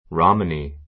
Romany rɑ́məni ラ マニ 名詞 複 Romanies rɑ́məniz ラ マニ ズ ❶ ロマ, ジプシー 参考 ヨーロッパ各地に散在する漂泊 ひょうはく 民族の人.